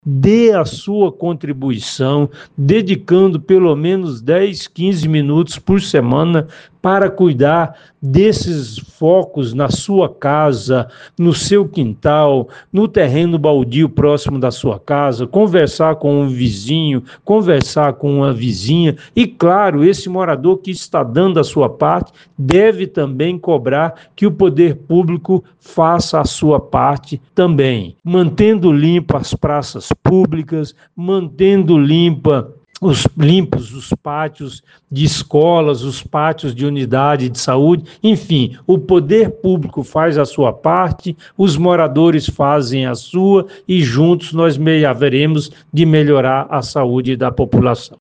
Em entrevista à FM Educativa MS o especialista deu mais esclarecimentos.